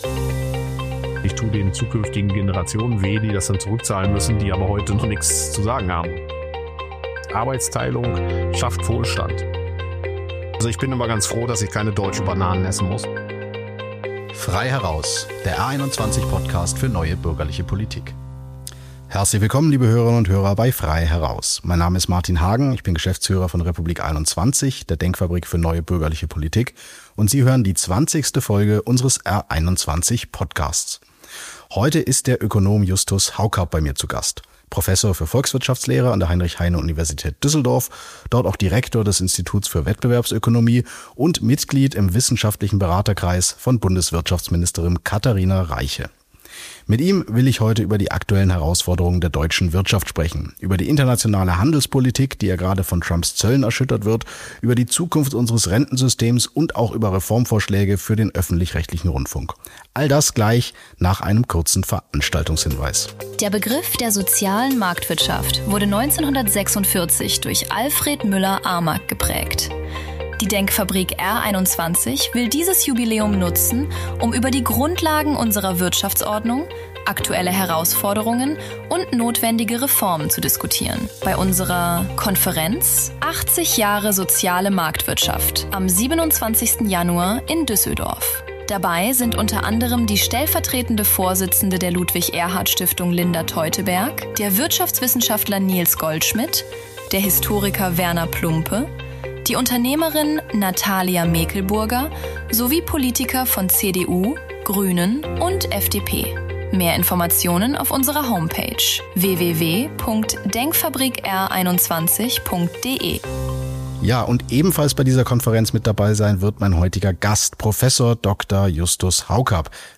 Der Ökonom fordert umfassende Reformen für den Wirtschaftsstandort Deutschland. Im Gespräch mit R21-Geschäftsführer Martin Hagen kritisiert er überzogene Datenschutzregeln, Politik auf Kosten künftiger Generationen und die jüngste Entscheidung des EU-Parlaments zum Freihandelsabkommen Mercosur. Für den öffentlich-rechtlichen Rundfunk hat er einen radikalen Vorschlag.